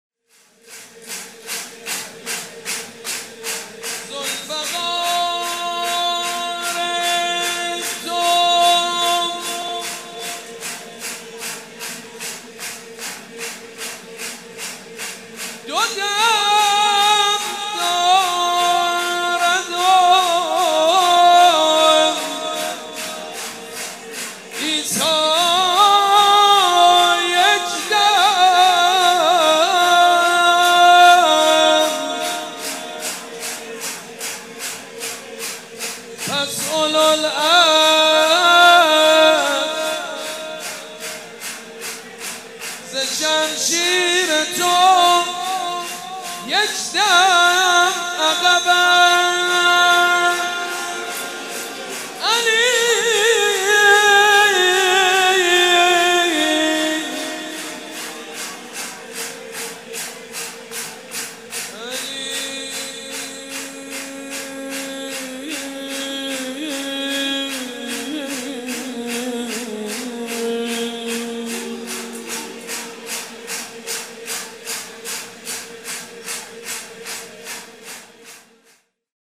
شب میلاد امام حسین (ع)در هیأت ریحانه الحسین(س)با مداحی حاج سید مجید بنی فاطمه برگزار شد